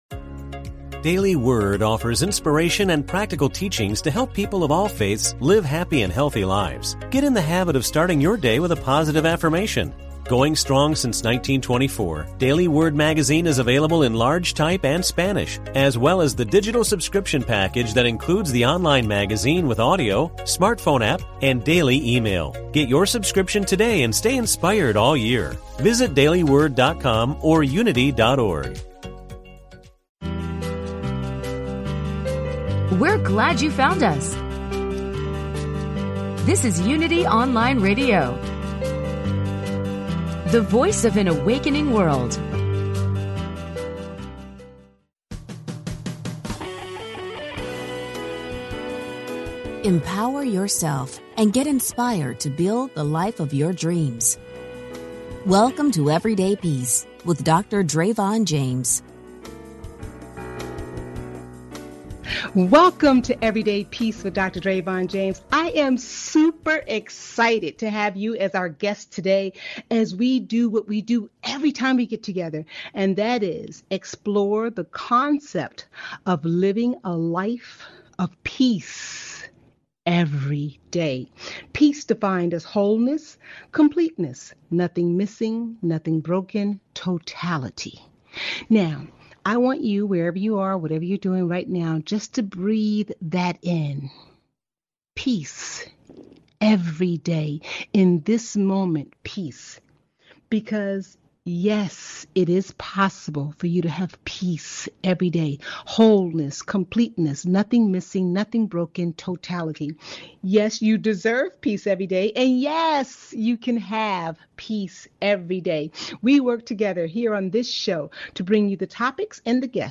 Monday, August 24, 2020, live show